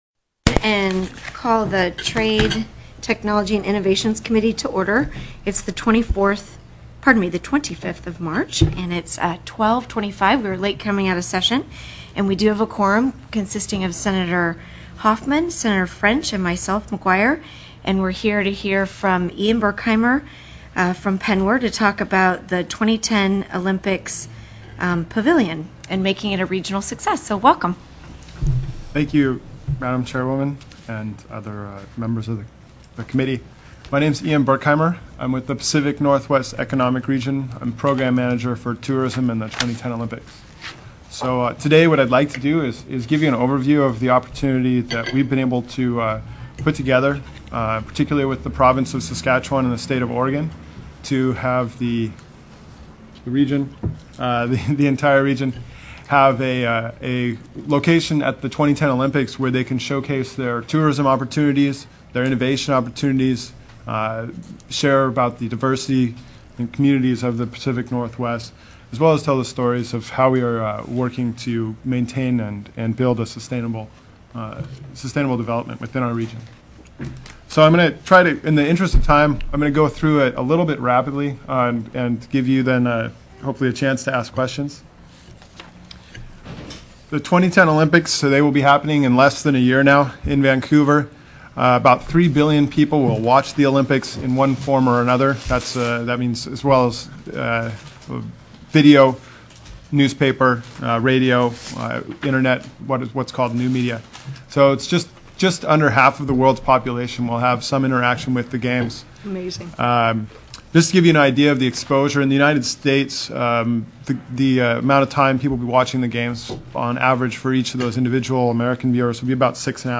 CHAIR MCGUIRE announced the business before the committee is to hear a presentation on making the 2010 Olympics a regional success.